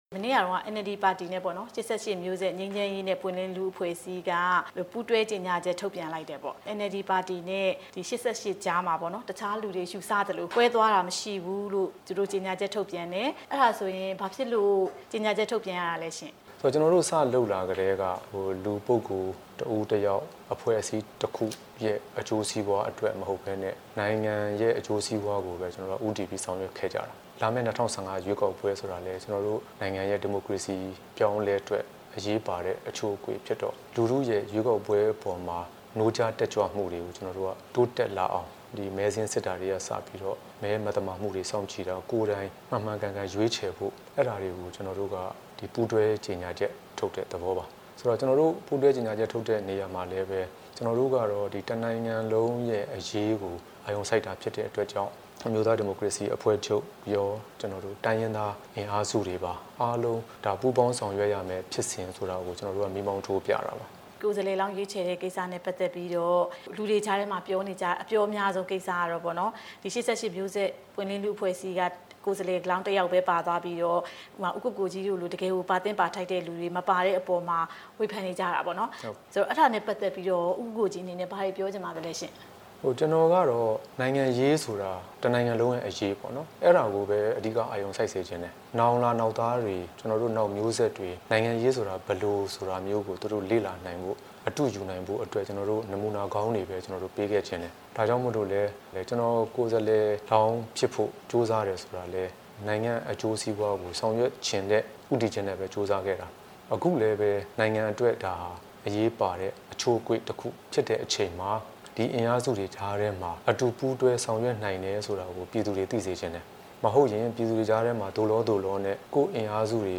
NLD နဲ့ ၈၈ အဖွဲ့တို့ရဲ့ထုတ်ပြန်တဲ့ အကြောင်း ဦးကိုကိုကြီးနဲ့ မေးမြန်း ချက်